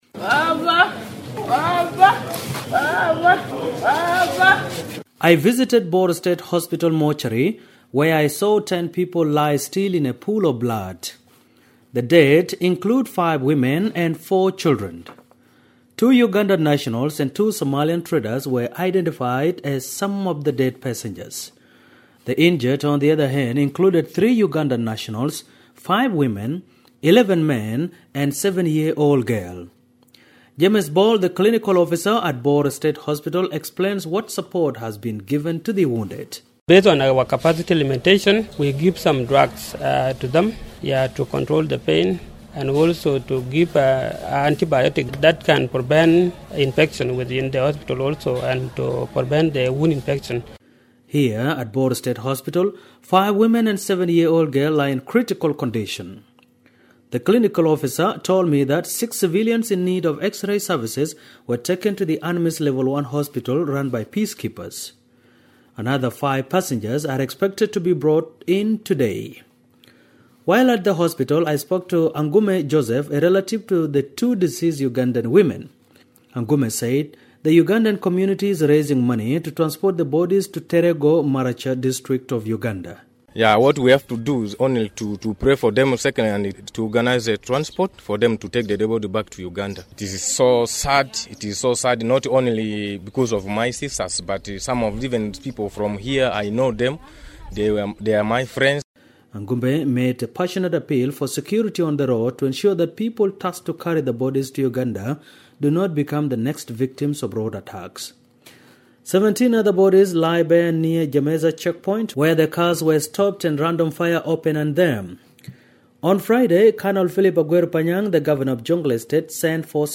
reports from Bor